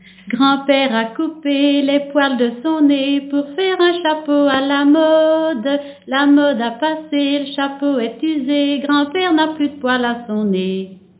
Genre : chant
Type : chanson d'enfants
Interprète(s) : Anonyme (femme)
Lieu d'enregistrement : Bouffioulx
Support : bande magnétique